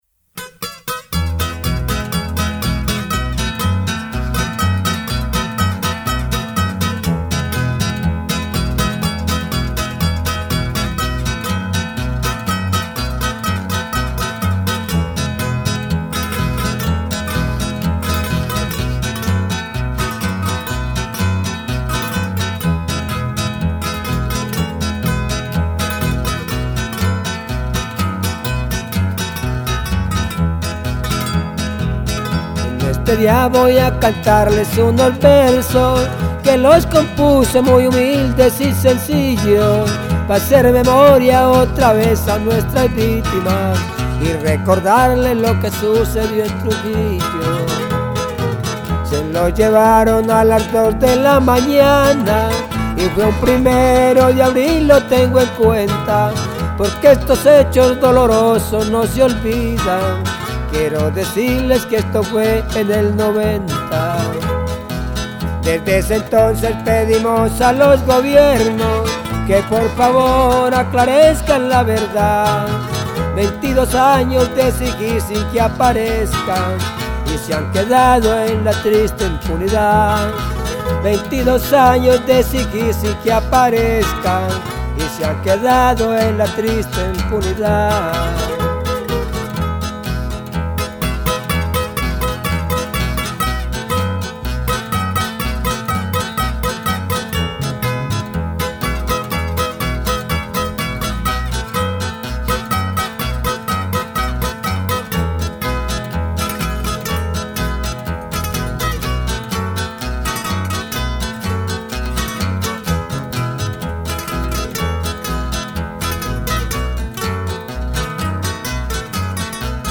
Canción
guitarra y bajo.
requinto.